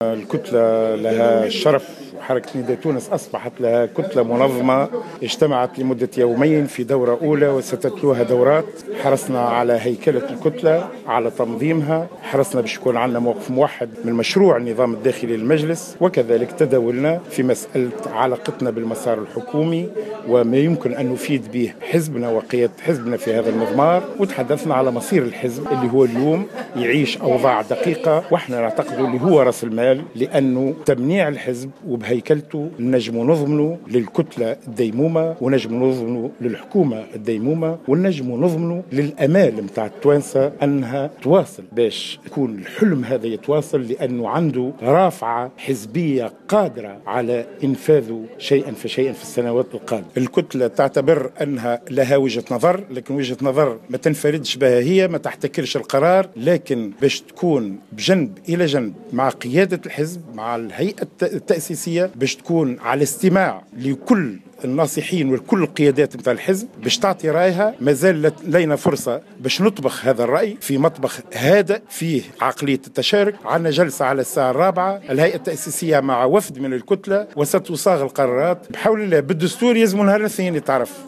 Le leader au mouvement Nidaa Tounes et député au sein de l’ARP, Khemais Ksila, a déclaré ce dimanche 4 janvier 2015 dans une intervention sur les ondes de Jawhara FM, que le parti a discuté lors de sa réunion à Hammamet de la composition du prochain gouvernement.